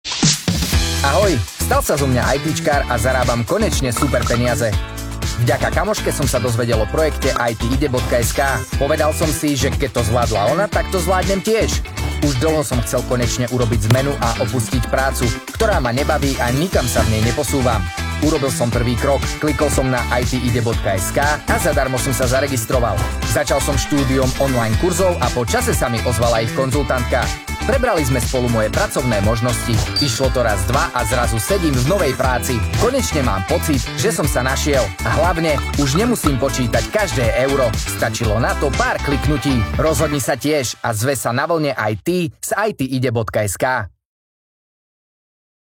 Hlas do reklamy (voiceover)
(vyčistenie nahrávky od nádychov rôznych ruchov a zvuková postprodukcia je samozrejmosťou)